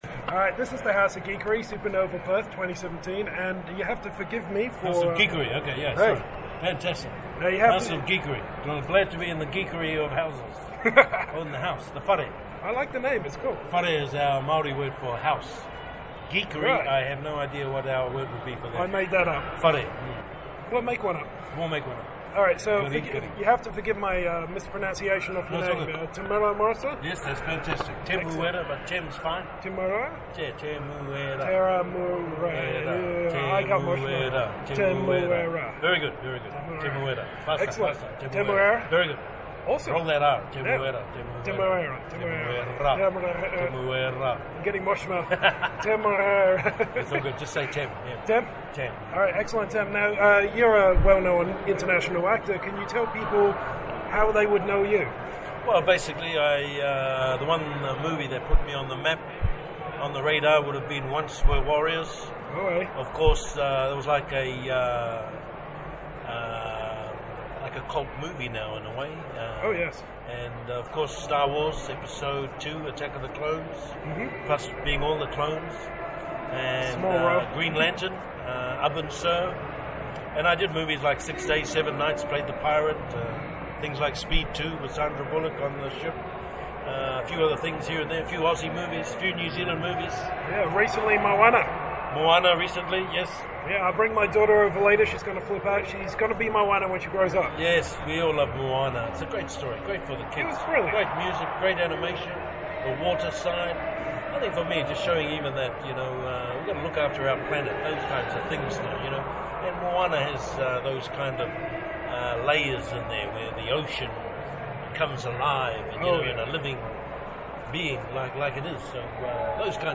Apologies for the audio on this one, we got assaulted by an evil PA.
Category : Convention, Interviews